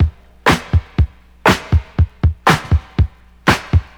• 120 Bpm HQ Breakbeat E Key.wav
Free drum loop - kick tuned to the E note. Loudest frequency: 1010Hz
120-bpm-hq-breakbeat-e-key-26n.wav